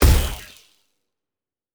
Sci Fi Explosion 21.wav